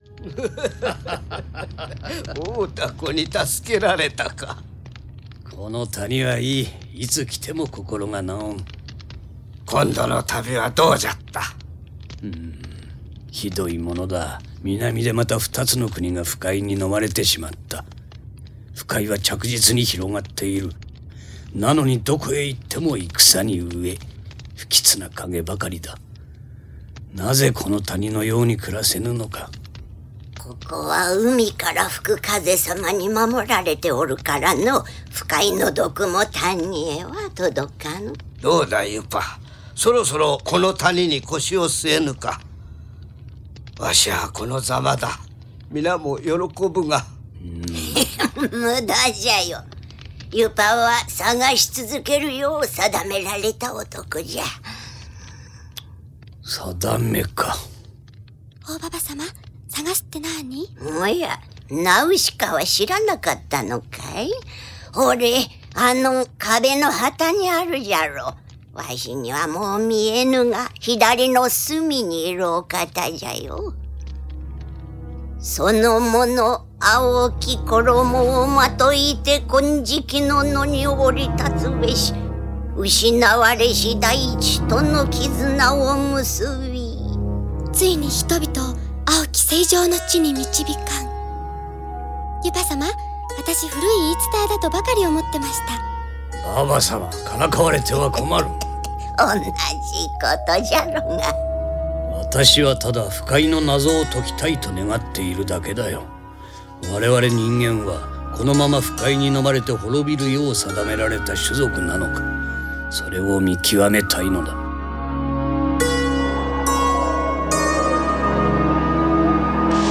Ils sont 4 : l’homme du début (Maître Yupa), la jeune fille (Nausicaä), une vieille dame que l’on devine mi sage, mi sorcière (O-Baba), et un vieillard (Maître Jill, père de Nausicaä).